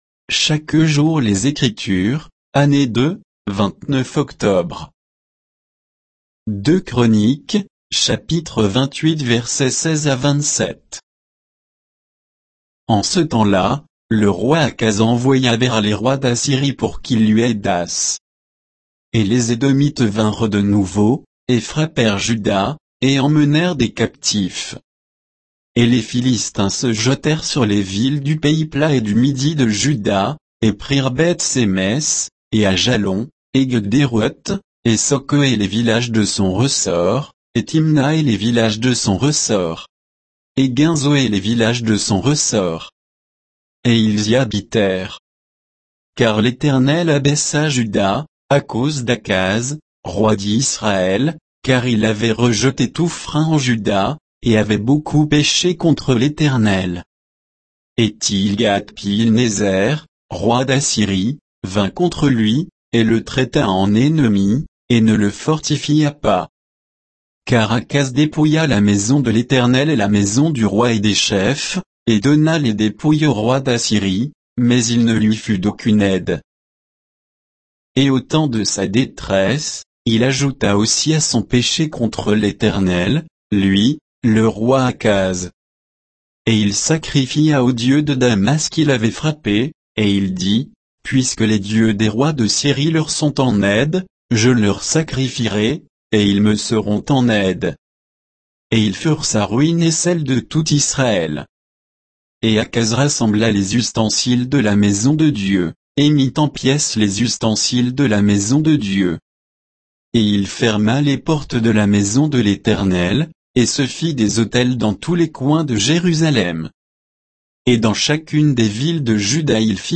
Méditation quoditienne de Chaque jour les Écritures sur 2 Chroniques 28